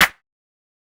TS Perc_9.wav